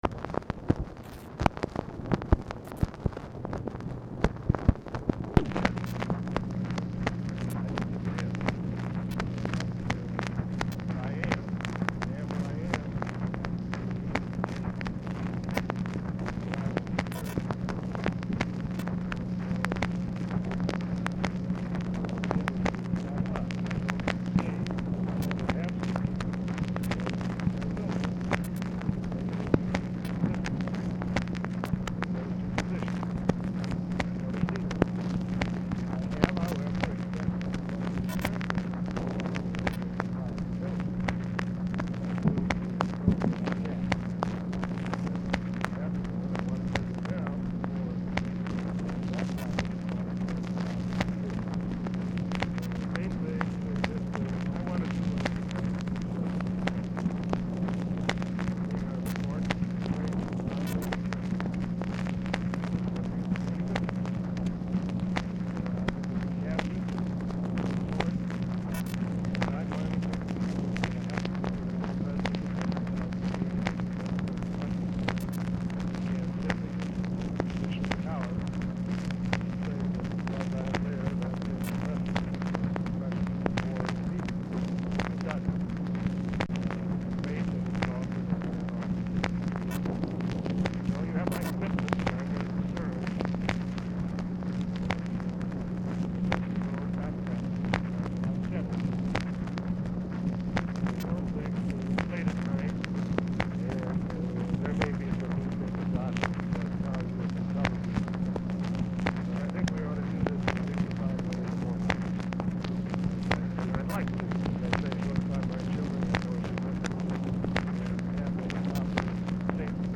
POOR SOUND QUALITY; GOLDBERG IS ALMOST INAUDIBLE
Format Dictation belt
Specific Item Type Telephone conversation